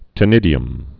(tĭ-nĭdē-əm)